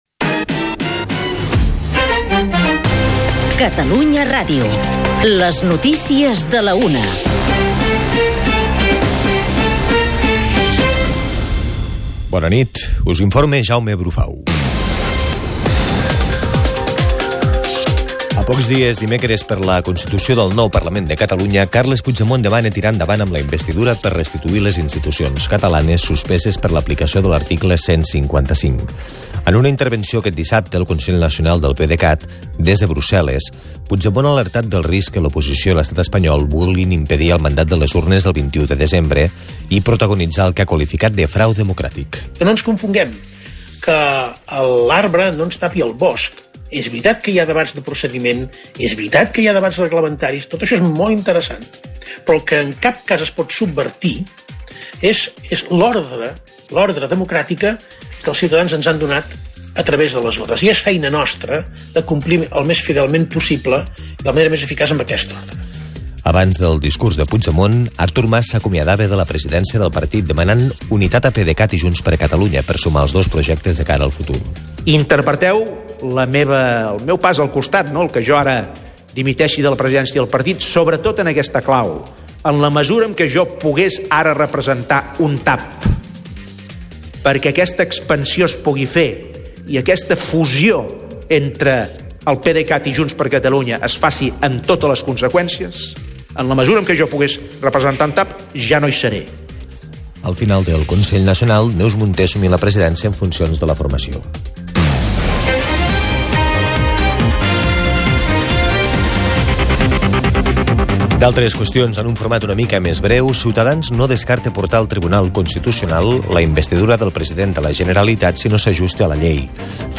bandes sonores
Inclou l'efecte Sensurround 4.